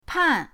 pan4.mp3